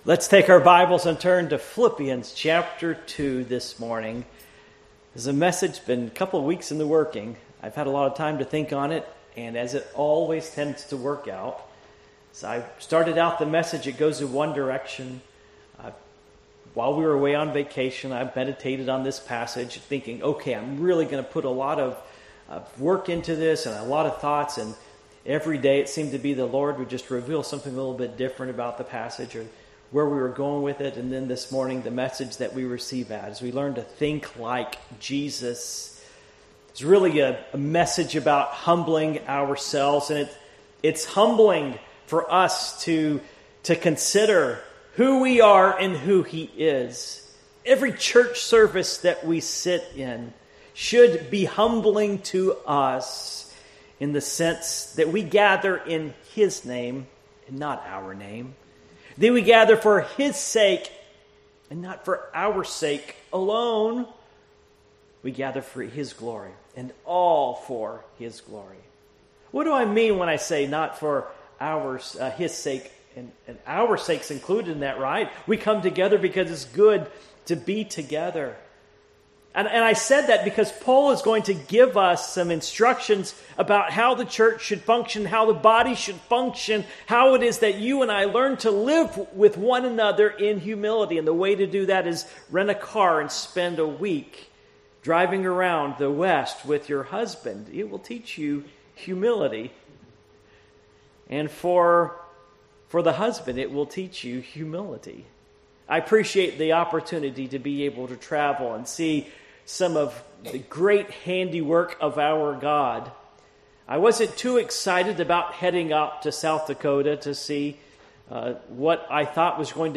Passage: Philippians 2:5-11 Service Type: Morning Worship